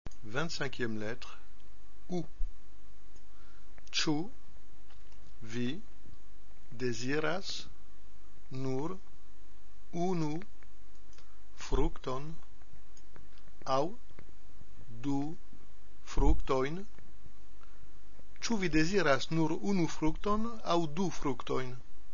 25 - U OU
-2) la phrase prononcée lentement en séparant bien les mots,
-3) la phrase prononcée normalement.